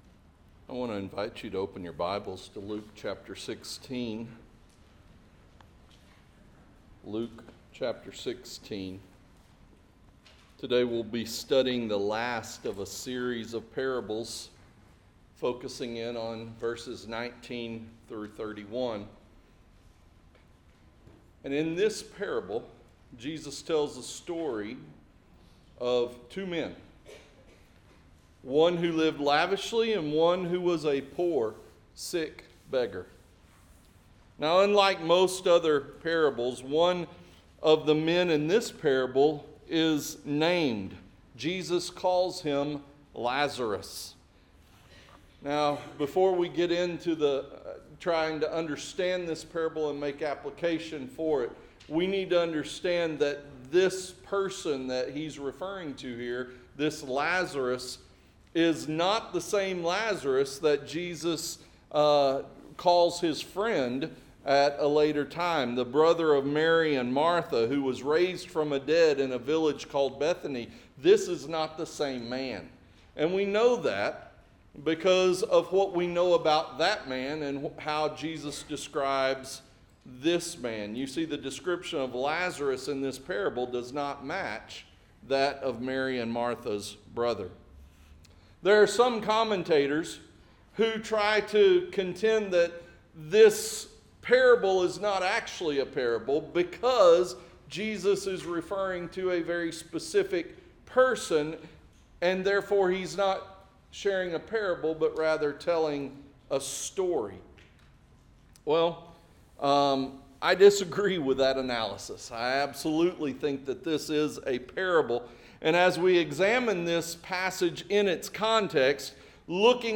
Heaven or Hell-The Kingdom of God Manifested – Temple Baptist Church of Rogers, AR